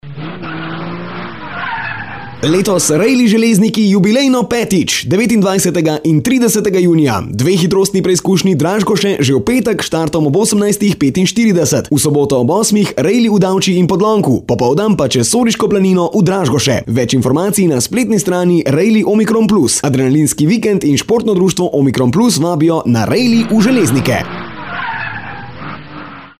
Promocijski oglas 5. Rally Železniki
Od ponedeljka naprej, pa seveda do dogodka, boste pri našem medijskem pokrovitelju Radio Sora lahko slišali promocijski oglas za 5. Rally Železniki.